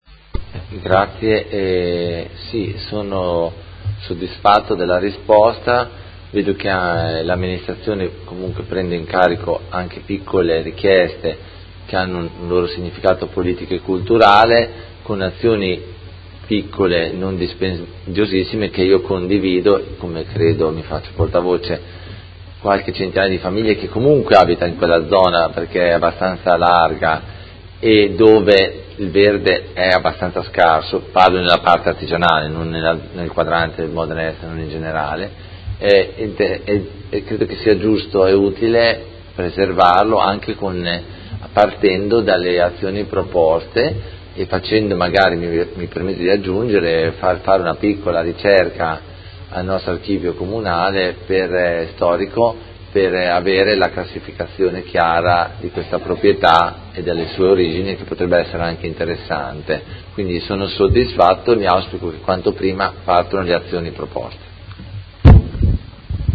Seduta del 26/04/2018 Replica a risposta Assessore Guerzoni. Interrogazione del Consigliere Carpentieri (PD) avente per oggetto: La ghiacciaia di Modena est